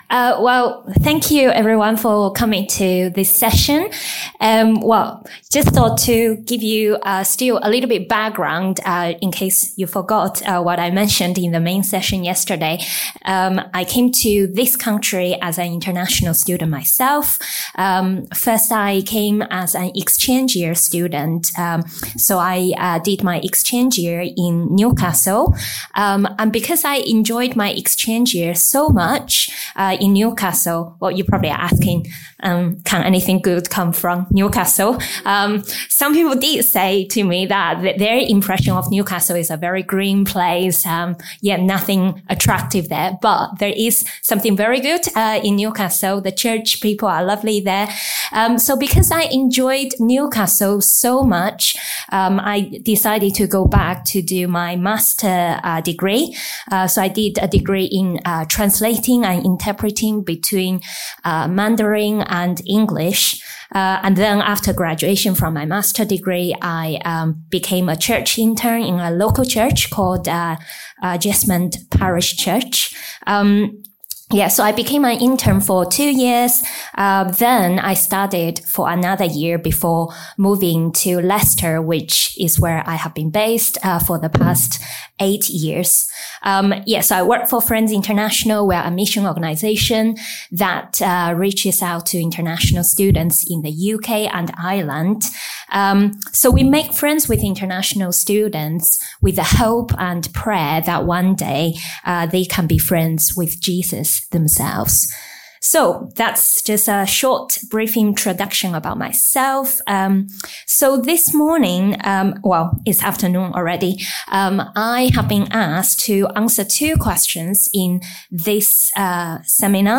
How can we welcome overseas visitors, who are only here for a short while, and then prepare them to go home? A seminar from the 2025 Leaders' Conference.